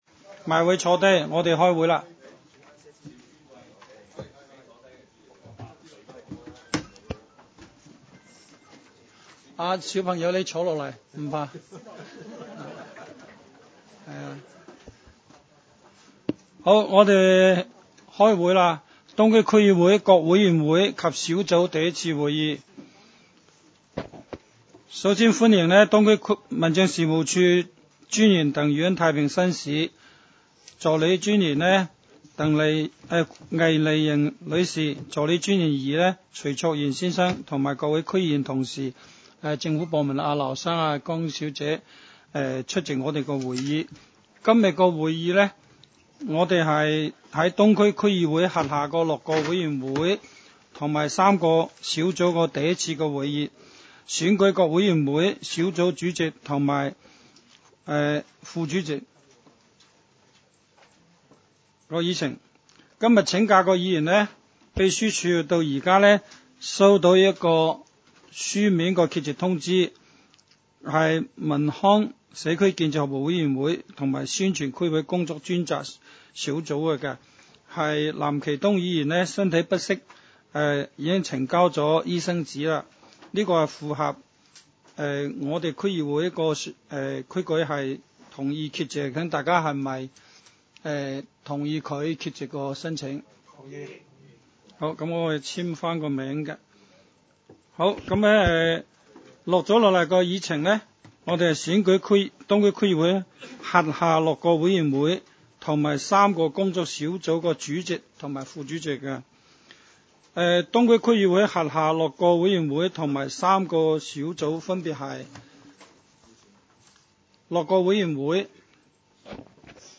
委员会会议的录音记录
地区设施管理委员会第一次会议 日期: 2018-01-02 (星期二) 时间: 下午2时30分 地点: 香港西湾河太安街 29 号 东区法院大楼 11 楼东区区议会会议室 议程 讨论时间 I 推选委员会主席及副主席 0:17:04 全部展开 全部收回 议程:I 推选委员会主席及副主席 讨论时间: 0:17:04 前一页 返回页首 如欲参阅以上文件所载档案较大的附件或受版权保护的附件，请向 区议会秘书处 或有关版权持有人（按情况）查询。